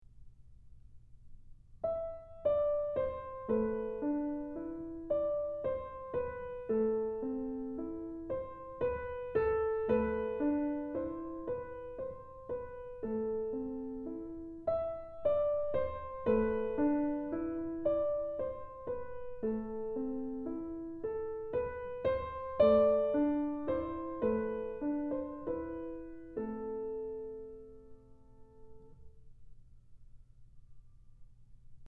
Time Period: ROMANTIC (1830-1900)